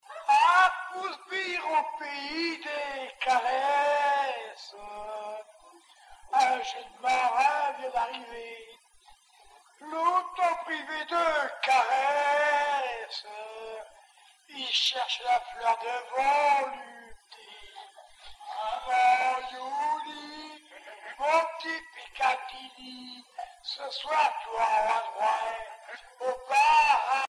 Localisation Fécamp
Genre strophique
Chanson maritimes